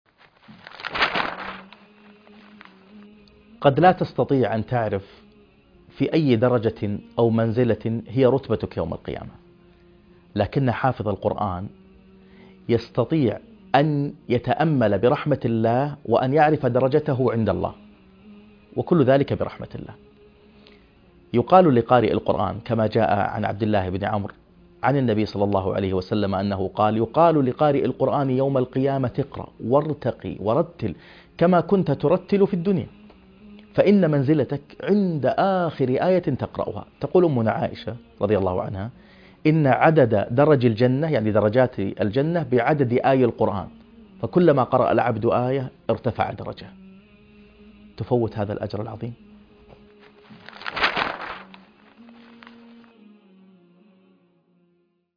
قرآناً عجبا الموسم 2 كلما قرأ العبد آية إرتفع درجة - الحلقة 13 - القاريء ناصر القطامي